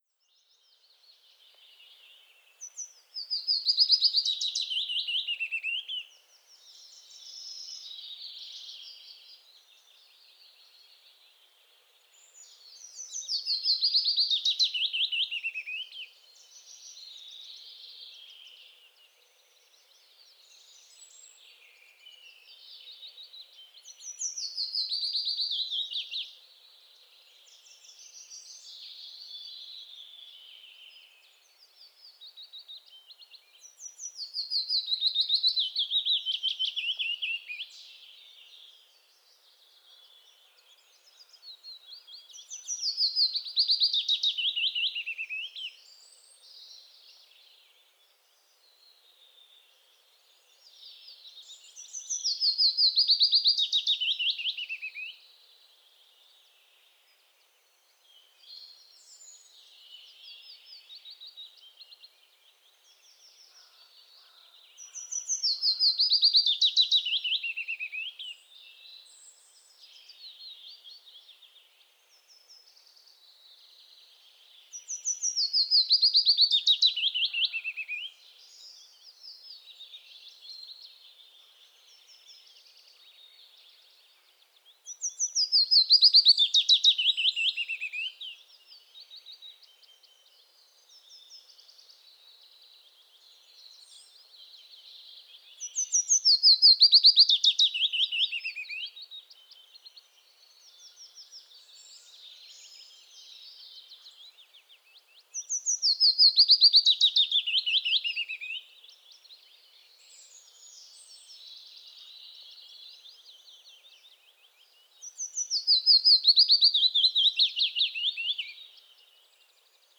Kuuntele: Pajulinnun laulu on suruisa
Pajulintu (Phylloscopus trochilus) on Suomen yleisin lintu. Sen laulu muistuttaa peippoa, mutta soi mollissa.